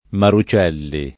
maru©$lli] cogn.